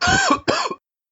mixkit-man-coughing-2224.ogg